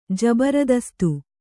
♪ jabaradastu